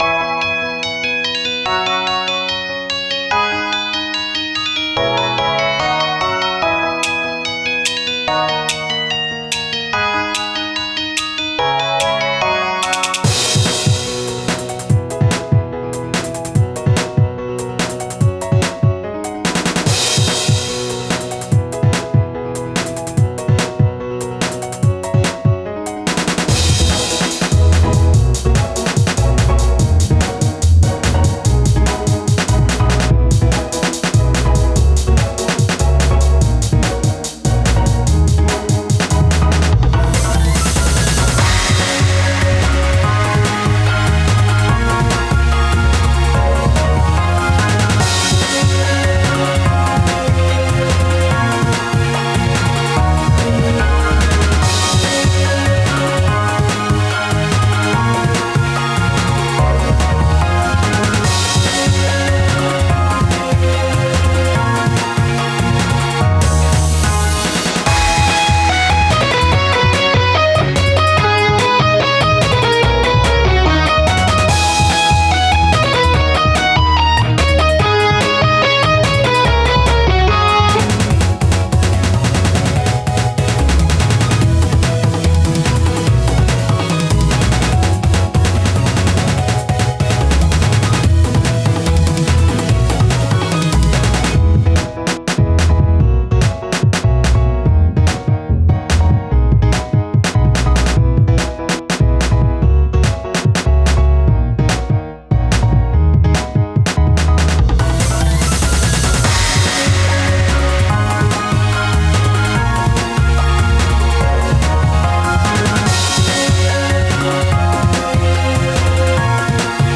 BPM：145